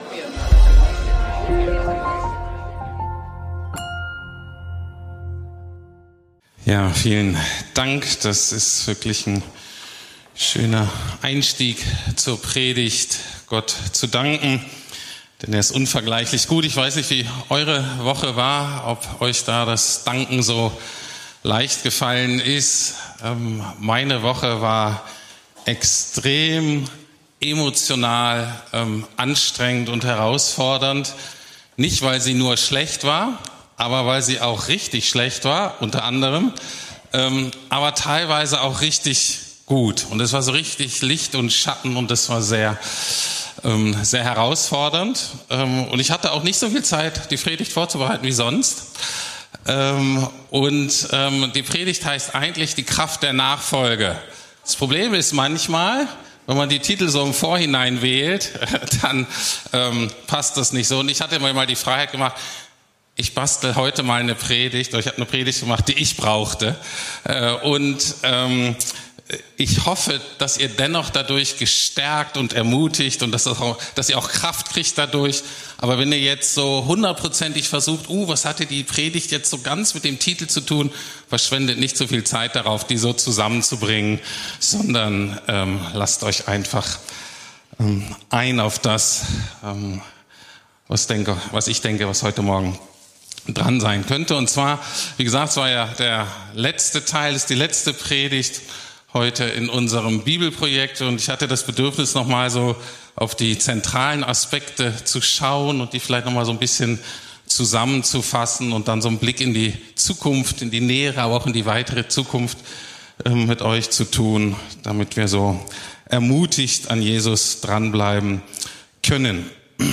Die Kraft der Nachfolge ~ Predigten der LUKAS GEMEINDE Podcast